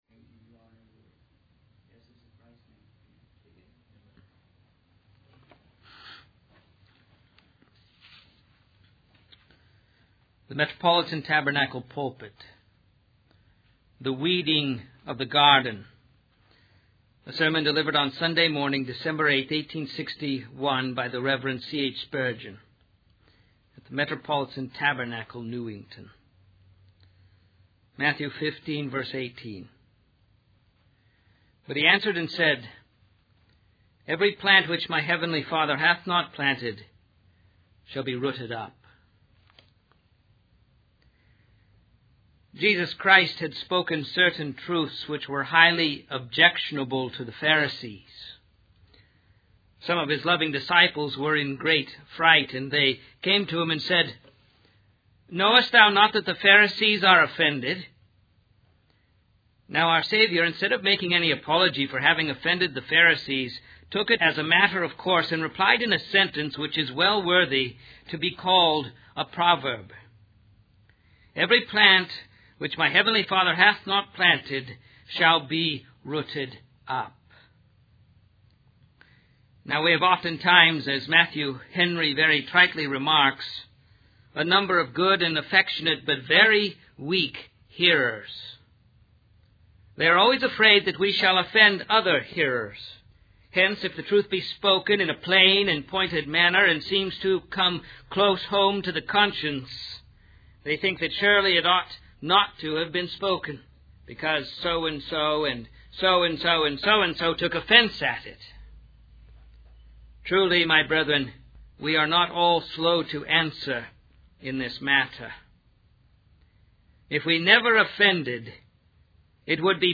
The sermon concludes with a plea for those who feel unworthy or hopeless to come to Christ and receive his salvation.